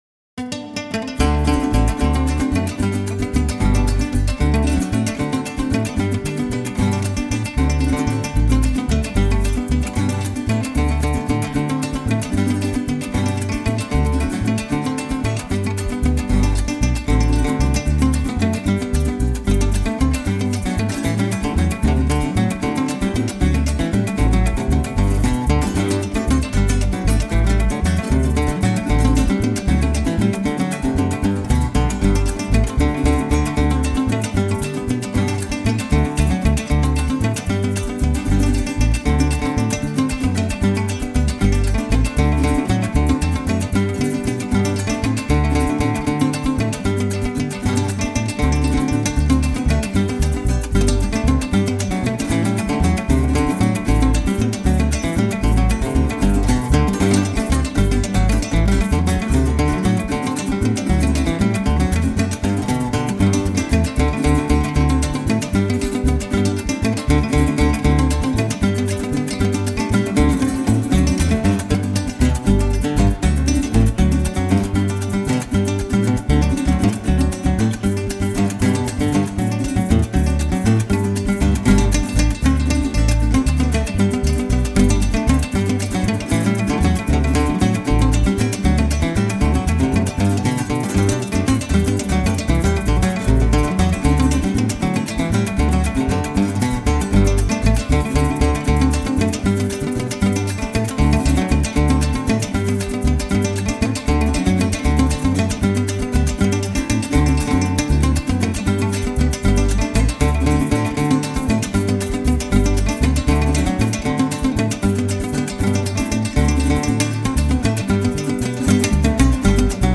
La música de América Latina